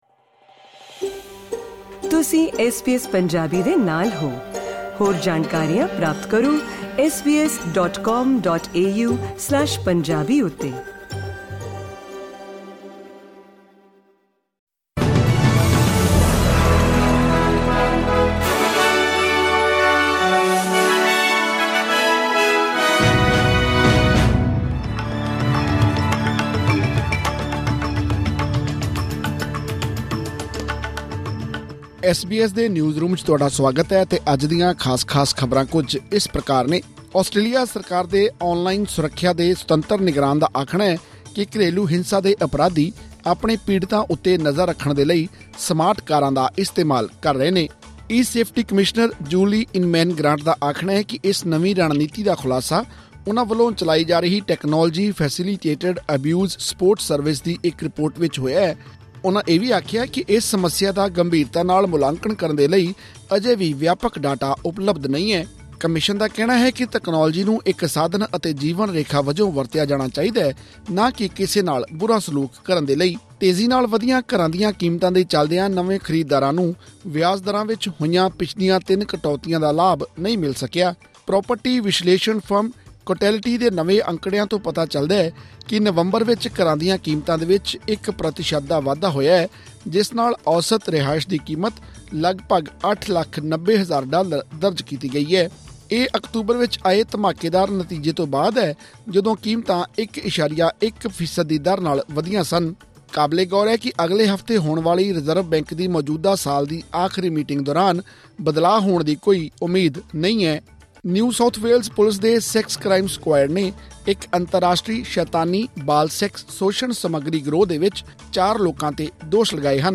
ਖ਼ਬਰਨਾਮਾ : ਘਰਾਂ ਦੀਆਂ ਕੀਮਤਾਂ ਵਿੱਚ ਵਾਧਾ ਜਾਰੀ, ਨਵੇਂ ਖਰੀਦਦਾਰਾਂ ਨੂੰ ਨਹੀਂ ਮਿਲਿਆ ਵਿਆਜ ਕਟੌਤੀਆਂ ਦਾ ਲਾਭ